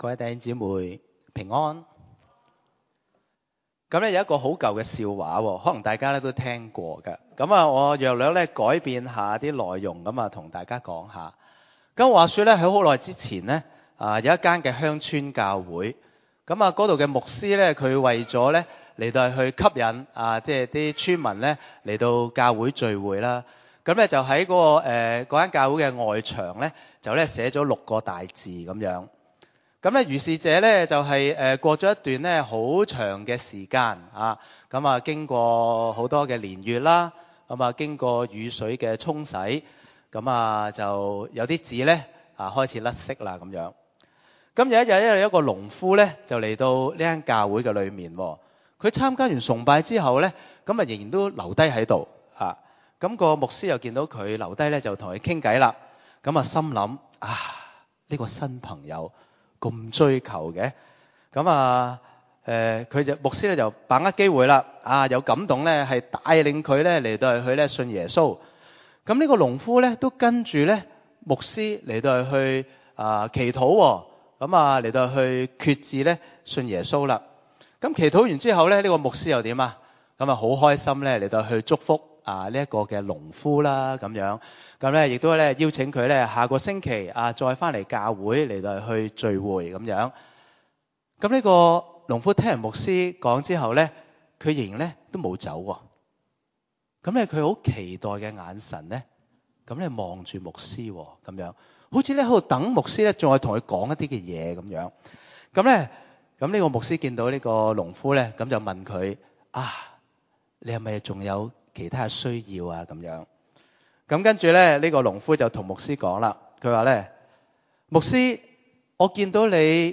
場所：週六崇拜